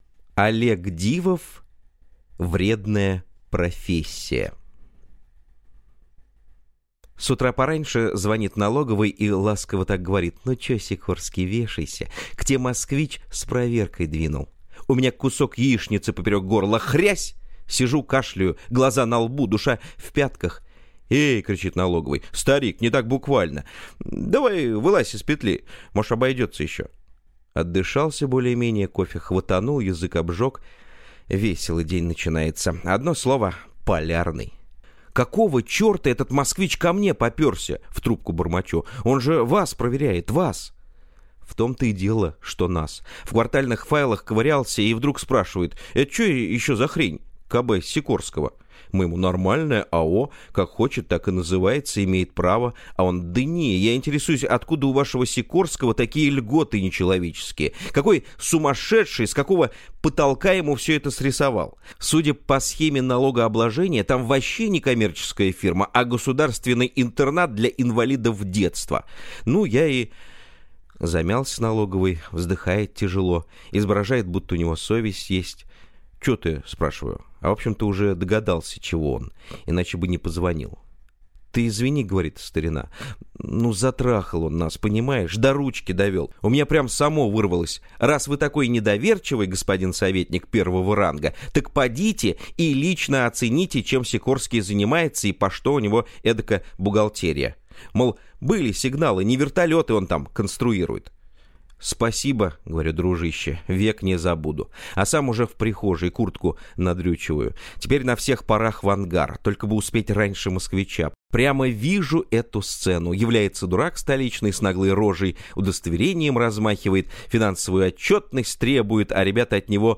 Аудиокнига Вредная профессия | Библиотека аудиокниг